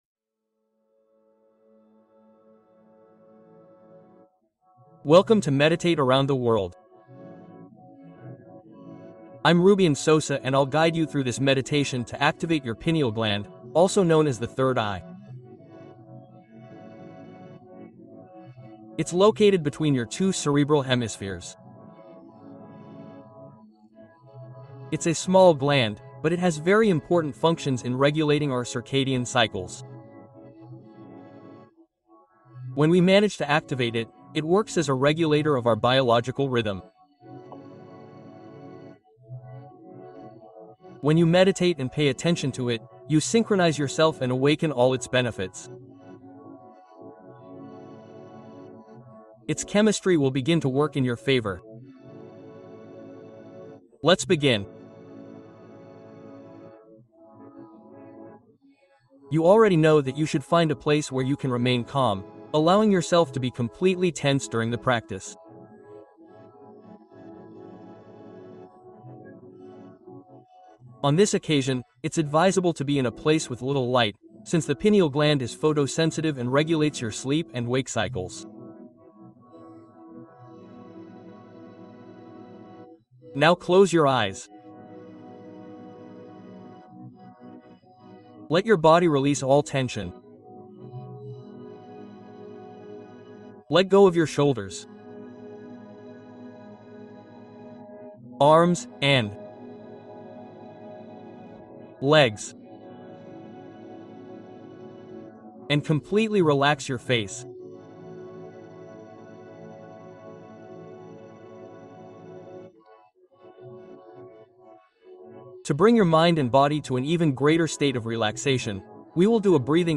Atención Plena en 10 Minutos: Meditación para Volver al Presente